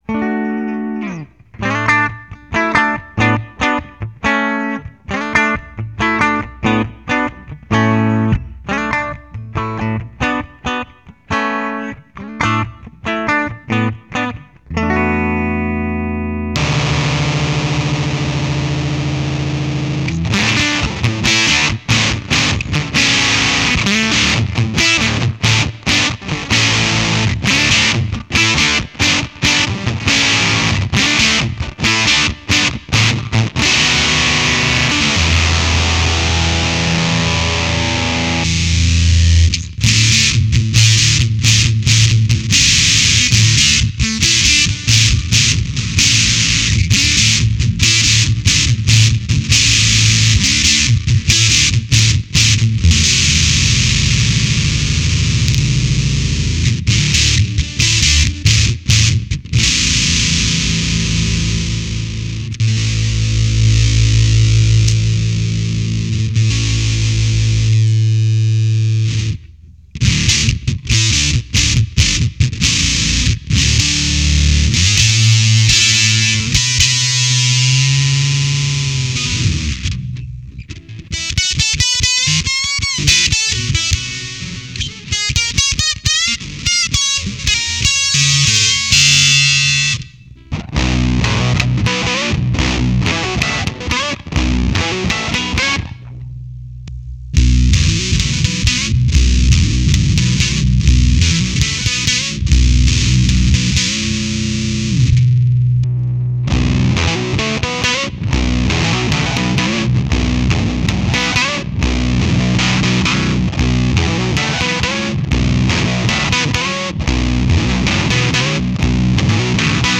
Its octave is very noticeable. Not a clean octave-up though – a fuzzy one.
One with a ton of mids that sounds very rich and another one that is mostly bass and treble. Audio clips Ibanez Standard Fuzz ( MP3 , 3.8 MB ) Ibanez Standard Fuzz (Ogg Vorbis, 2.7 MB ) The sample starts with a clean sound, then the Standard Fuzz is switched on with the mid-heavy sound in use. Later, the no-mids mode is on and pickups are changed. The guitar is an Ibanez 2027XVV .
ibanez-standard-fuzz.mp3